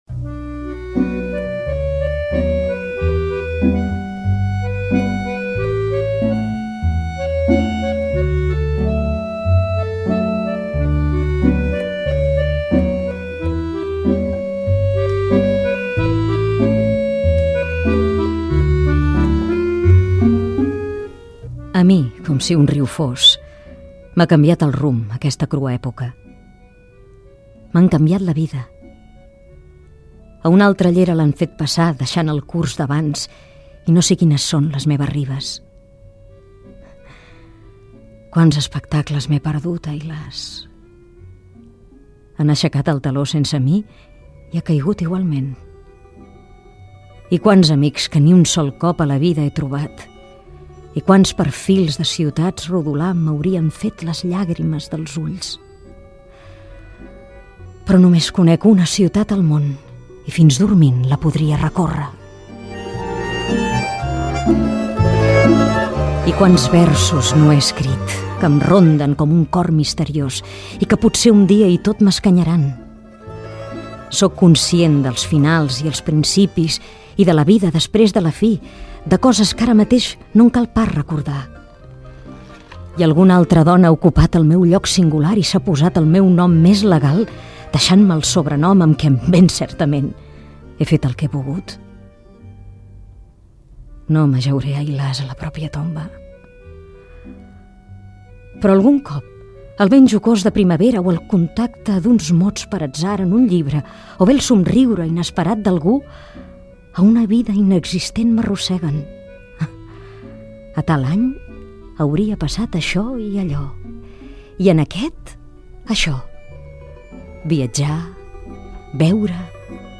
POEMA - Akhmàtova